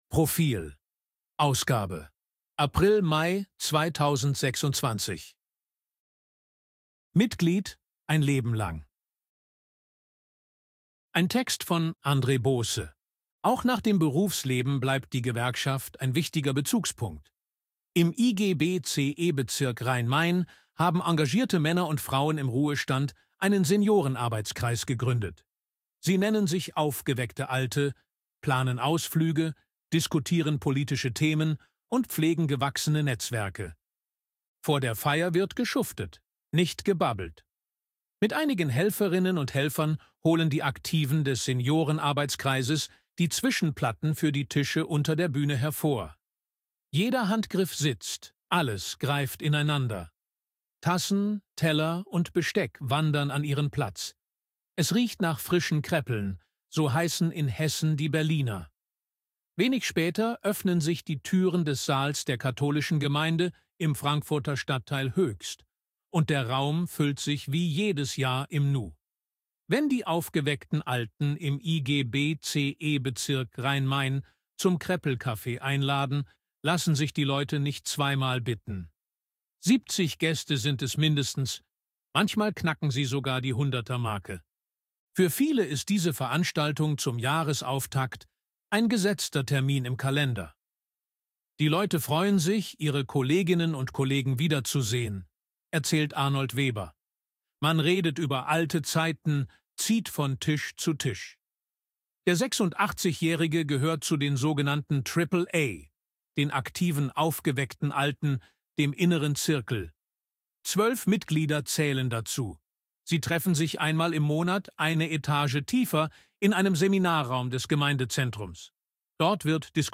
Artikel von KI vorlesen lassen
ElevenLabs_262_KI_Stimme_Mann_Portrait.ogg